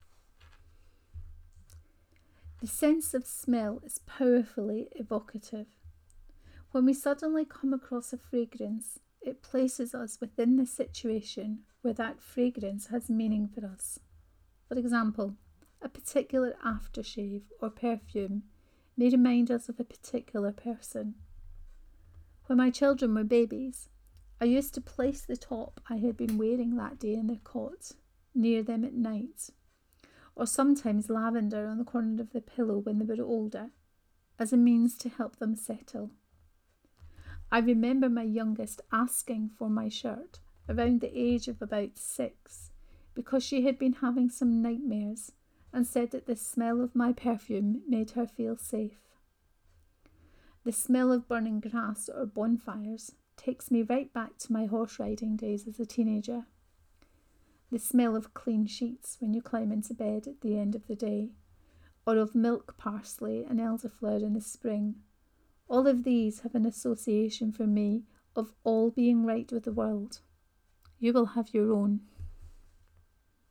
The Fragrance of God 3: Reading of this post.